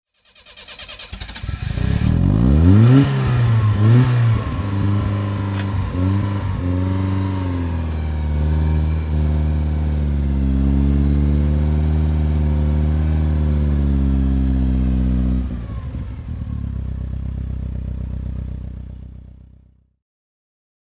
Lotus Esprit S2 (1979) - Starten und Leerlauf